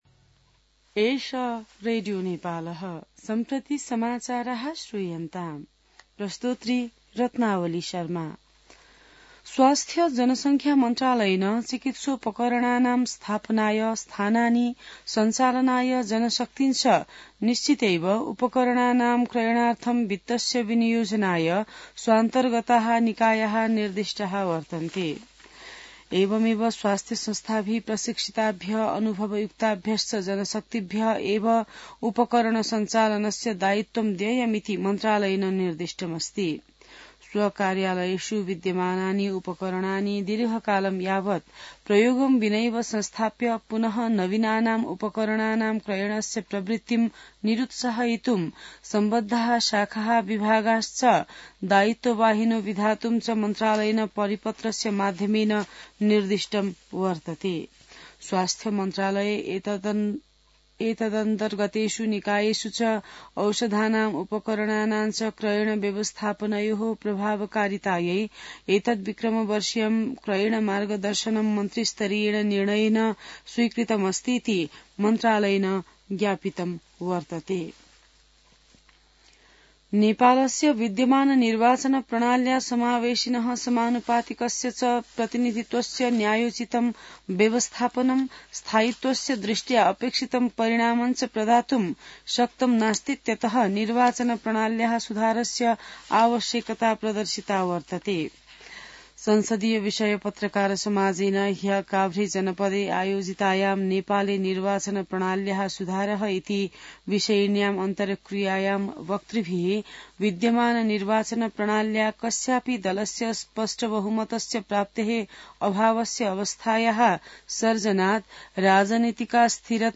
संस्कृत समाचार : ७ माघ , २०८१